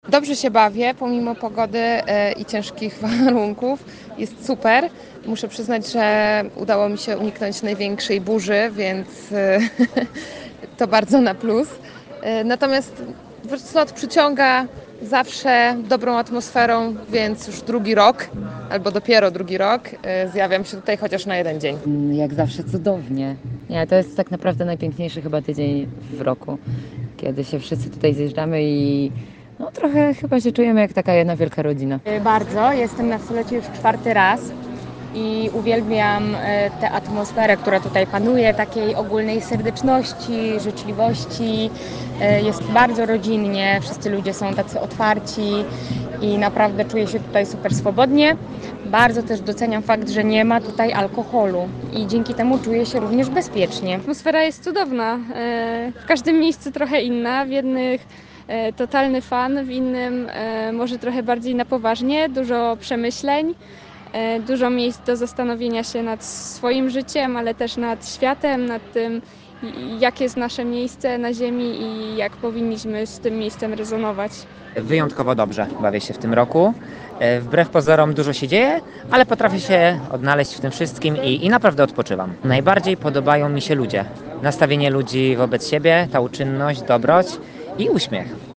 Zapytaliśmy uczestników festiwalu o wrażenia.
05-sonda-co-mysla-o-slocie.mp3